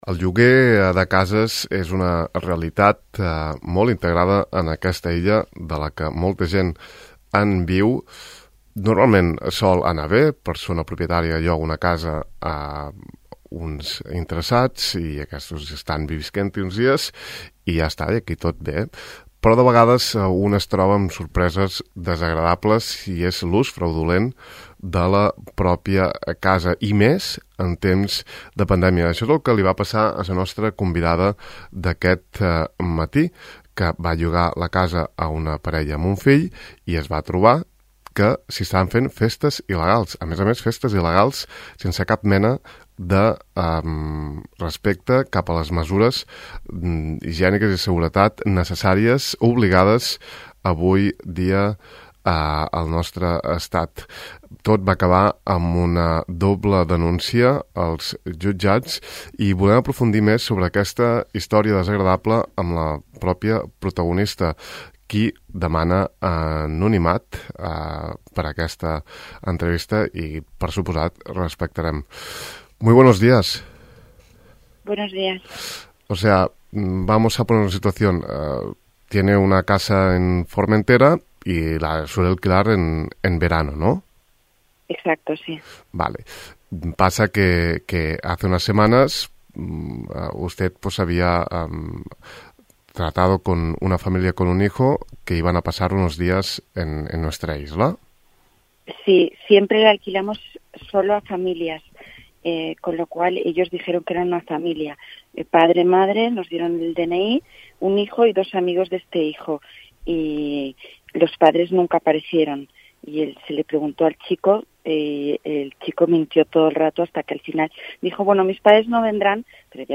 El que havia de ser un contracte de lloguer vacacional a una parella amb el seu fill, es va transformar en una festa amb música a tot volum i una gentada sense cap respecte per les mesures de control i prevenció de la covid. Al De Far a Far escoltem la propietària de l’habitatge turístic que ha denunciat festes il·legals i destrosses a la seva propietat per part d’uns incívics llogaters.